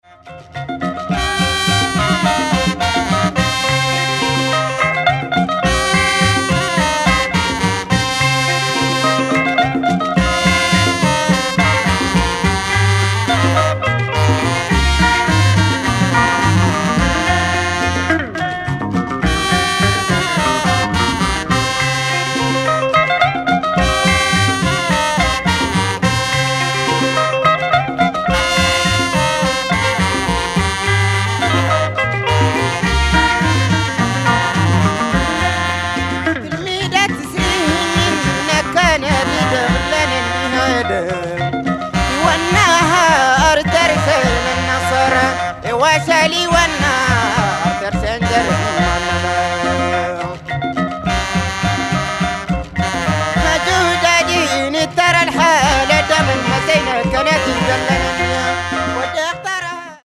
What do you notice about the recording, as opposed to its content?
is now available again with remastered audio